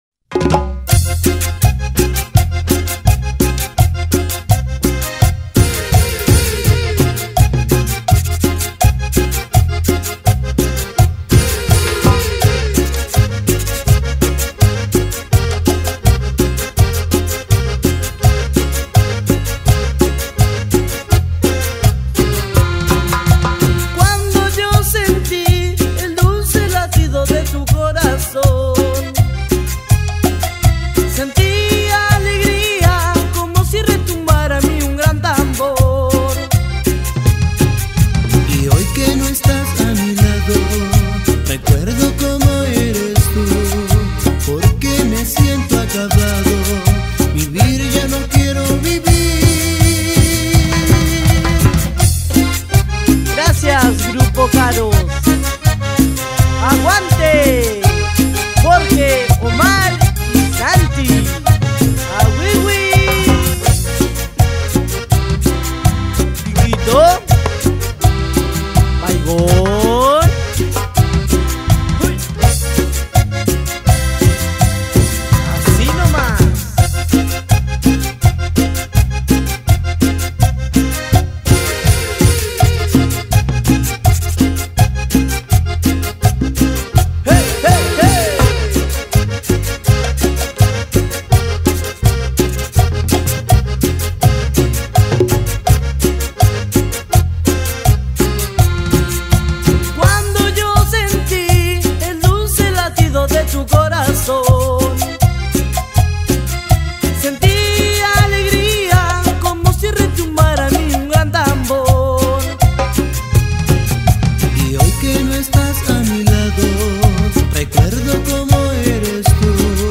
Carpeta: Cumbia y + mp3